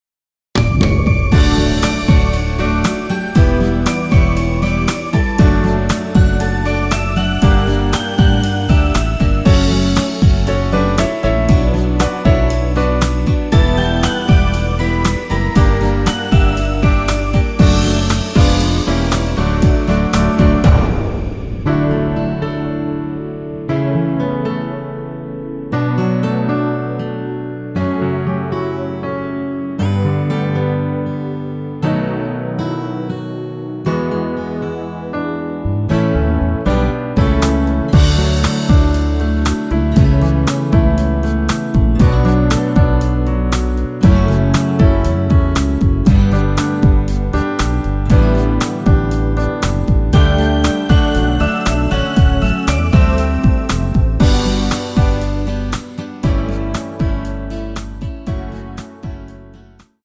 MR 입니다.
축가에 잘 어울리는 곡 입니다.
◈ 곡명 옆 (-1)은 반음 내림, (+1)은 반음 올림 입니다.
앞부분30초, 뒷부분30초씩 편집해서 올려 드리고 있습니다.